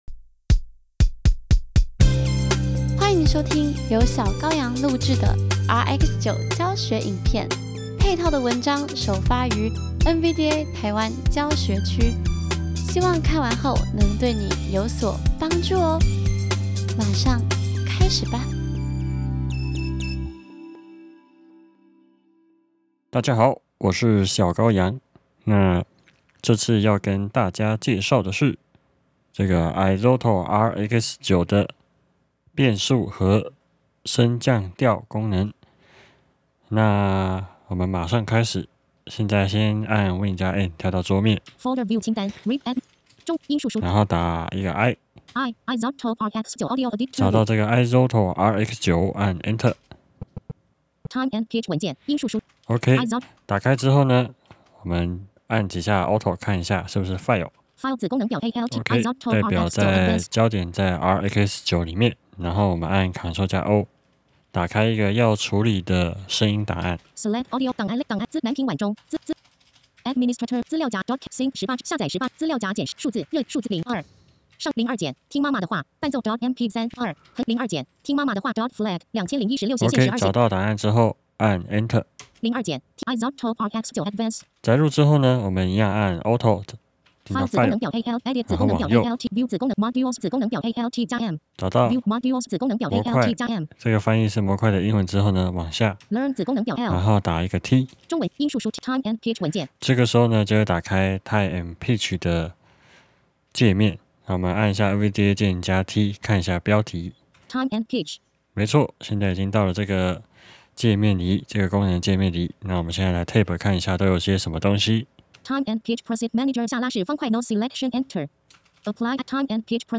想要跟著我操作，或是試聽升降調、變速的效果如何，可以觀看為你精心錄製的影片→ 點我到youtube觀看聲音處理的萬用工具izotope rx9-3 升降調和變速 當然，你也可以從這裡下載mp3回去欣賞喔→ 點我從小羔羊下載站收聽或下載 備用 點我下載
聲音處理的萬用工具izotope rx9-3 升降調和變速.mp3